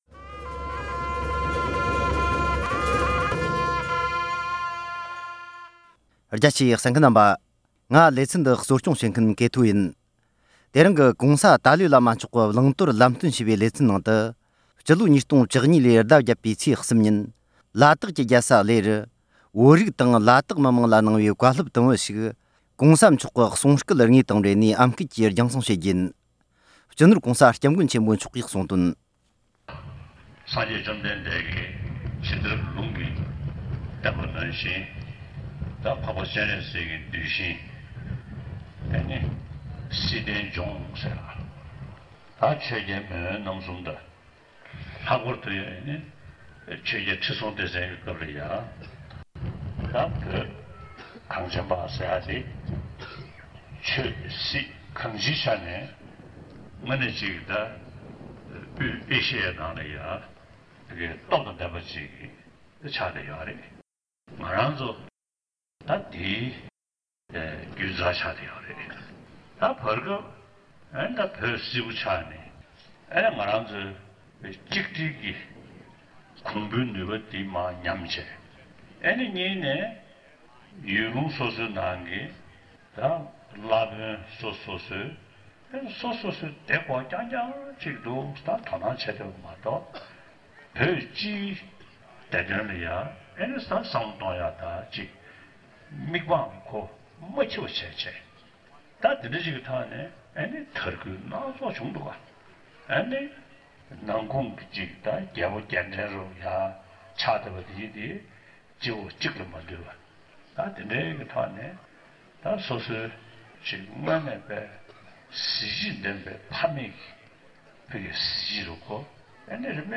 ༸གོང་མཆོག་ནས་བོད་ཀྱི་རིག་གཞུང་དར་རྒྱུད་སྐོར་བཀའ་སློབ་བསྩལ་གནང་ཡོད་པ།